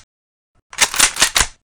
6f19f2c70f Divergent / mods / Blindsides Reanimation Pack / gamedata / sounds / weapons / mosin / bolt_new.ogg 38 KiB (Stored with Git LFS) Raw History
bolt_new.ogg